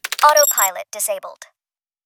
autoPilotDis.wav